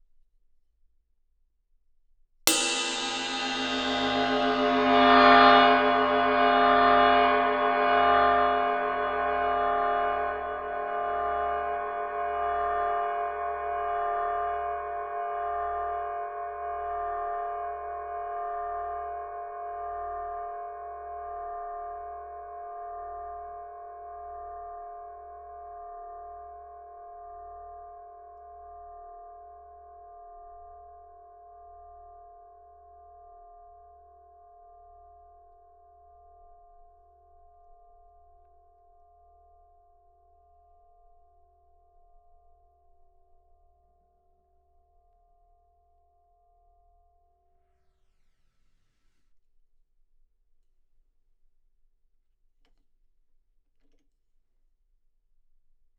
You then record a source and vary the mic position as the source sound decays, allowing you to pick up more of the detail in the decay of the sound.
I also experimented with moving the mic around the cymbal surface to capture different harmonics.
flying-cymbal-1.wav